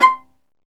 Index of /90_sSampleCDs/Roland - String Master Series/STR_Viola Solo/STR_Vla2 % + dyn